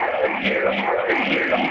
Index of /musicradar/rhythmic-inspiration-samples/140bpm